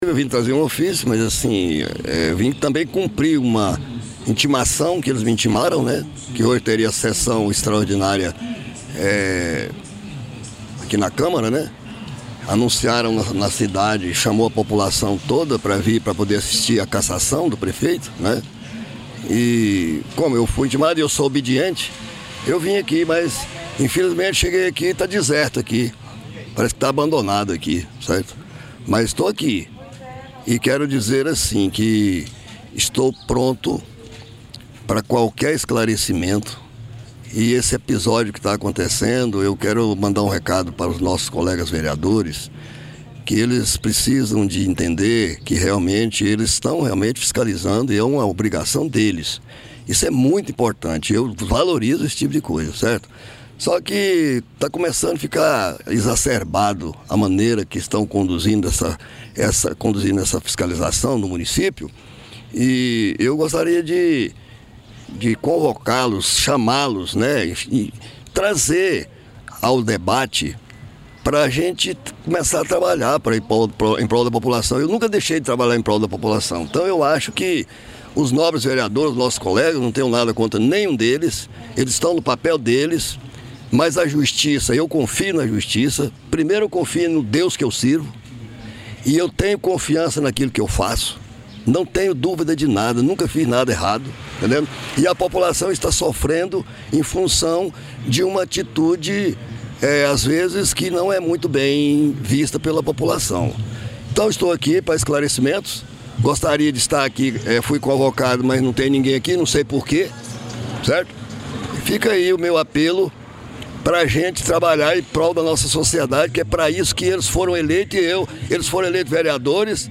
Áudio do prefeito Nelsinho